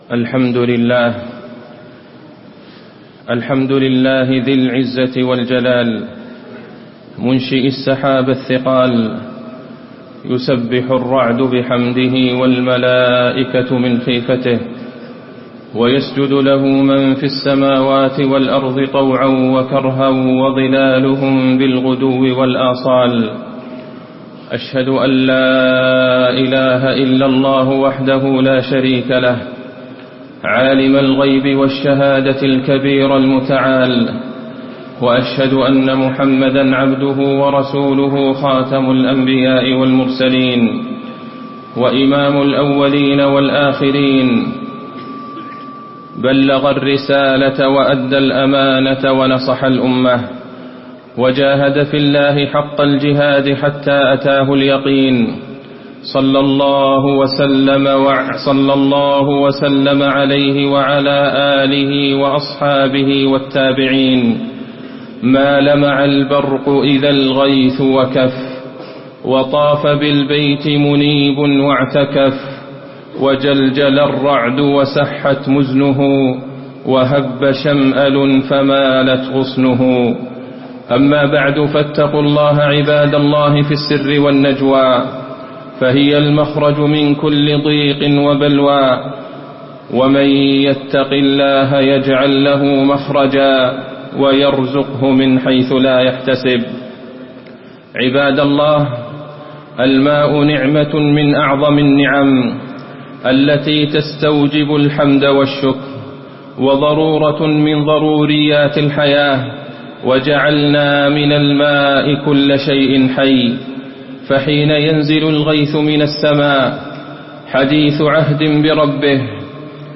خطبة الاستسقاء - المدينة- الشيخ عبدالله البعيجان
المكان: المسجد النبوي